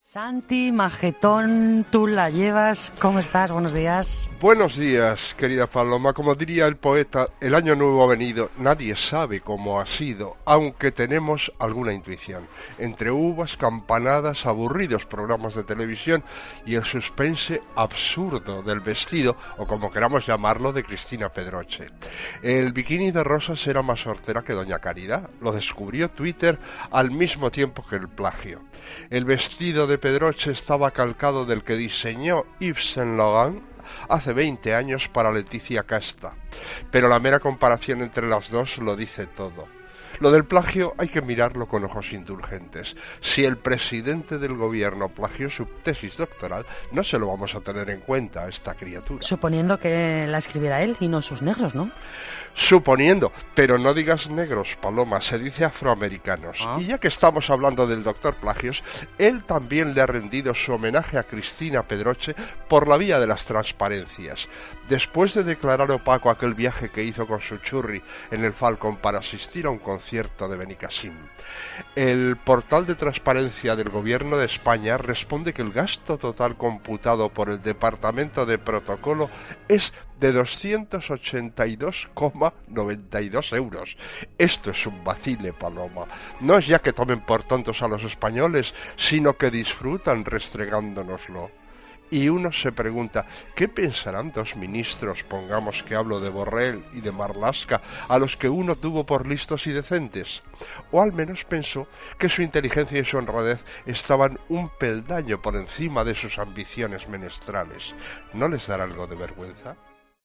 Analiza Santi González en 'Hererra en COPE' la actualidad del día.